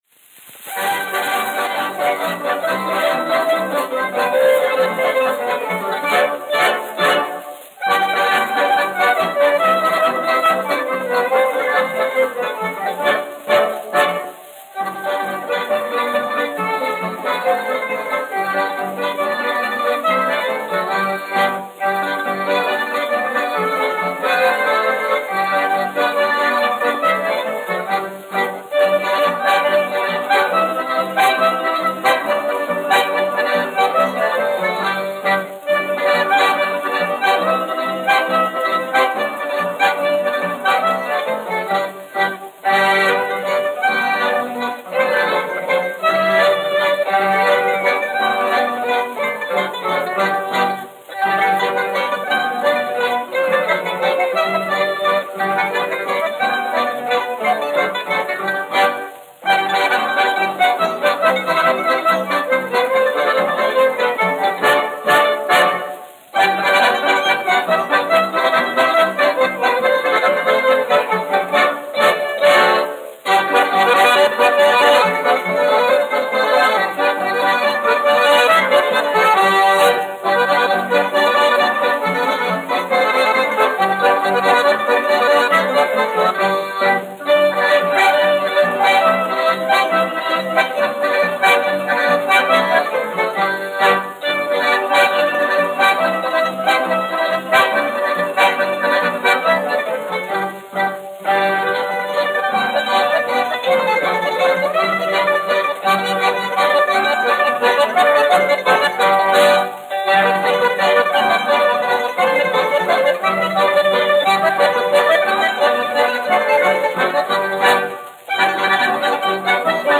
Sudmaliņas : latviešu tautas deja
1 skpl. : analogs, 78 apgr/min, mono ; 25 cm
Latviešu tautas dejas
Latvijas vēsturiskie šellaka skaņuplašu ieraksti (Kolekcija)